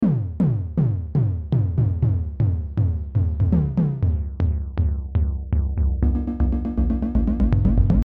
Here we’ve dialled in a classic synth tom sound in Steinberg Retrologue:
Synthesised toms are also great for adding movement to a track. You can even use automation to retune them whilst the track plays: